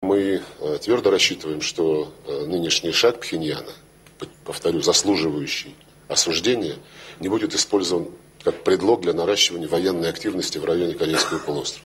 Диктор ТВ КНДР - о последних ядерных испытаниях